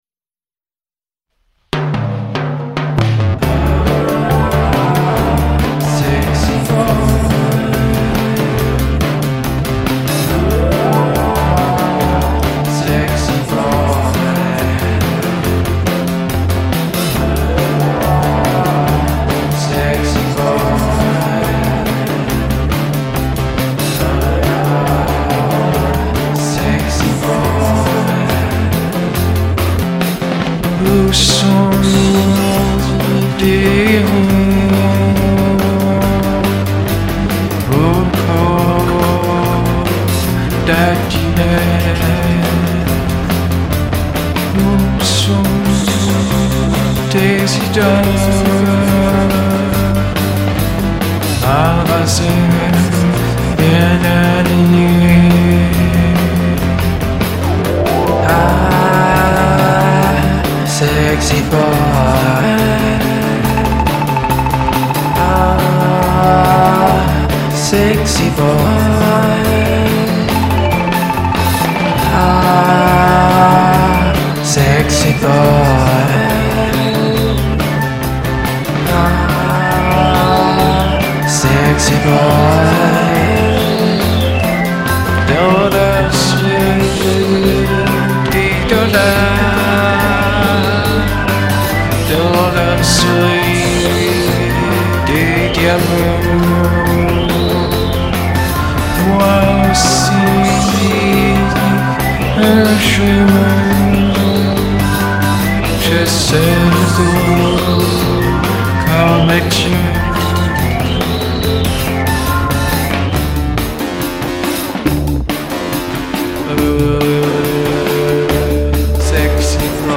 stralunata cover
woozy cover